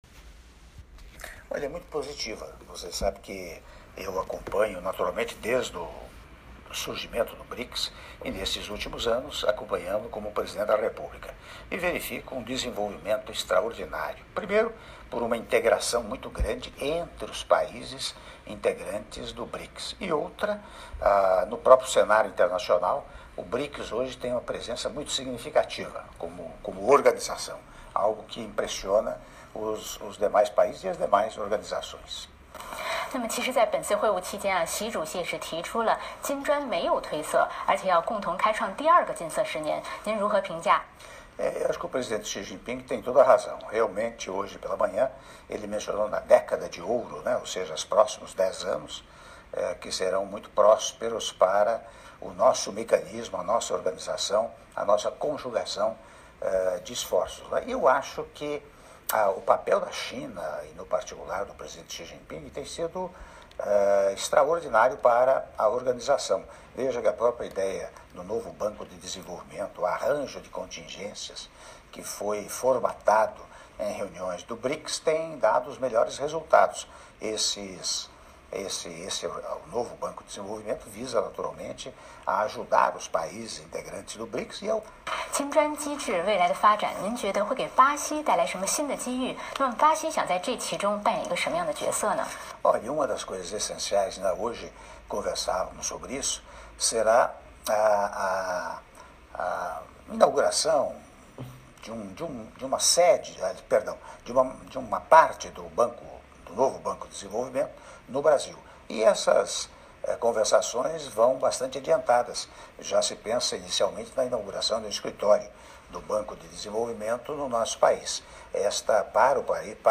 Áudio da entrevista exclusiva concedida pelo Presidente da República, Michel Temer, à Televisão Central da China - CCTV (02min20s)